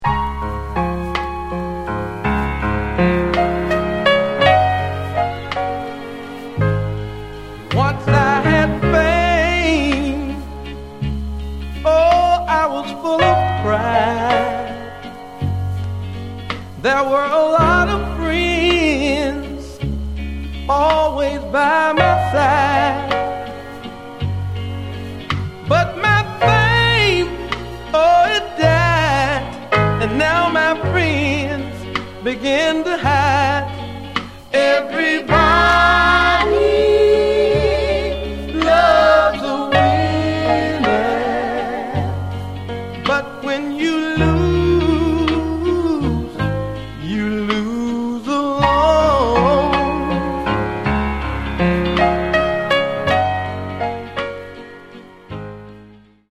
Genre: Deep Soul